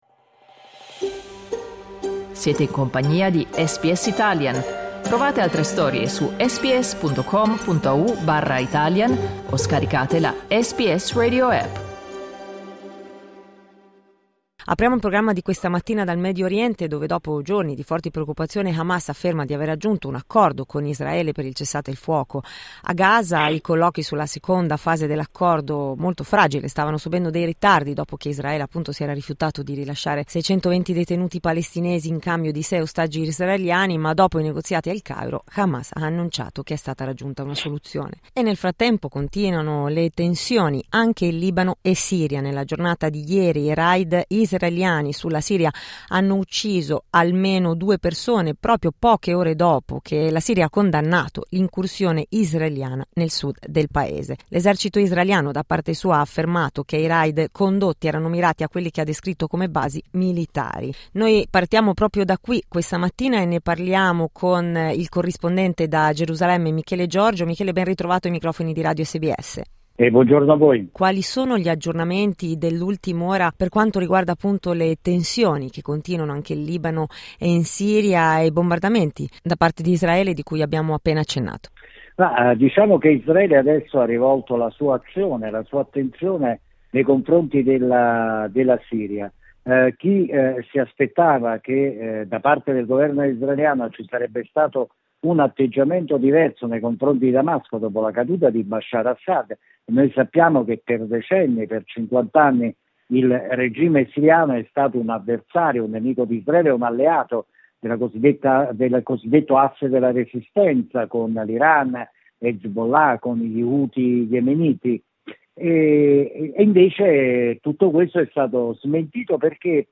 Ascolta l'approfondimento con il giornalista